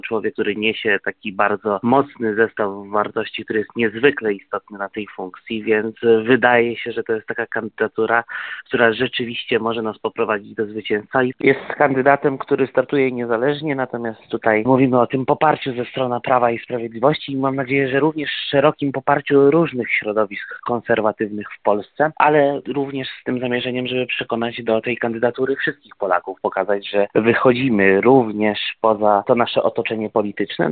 – Jest to bardzo dobry wybór – mówi poseł z ramienia PiS Michał Moskal (na zdj.).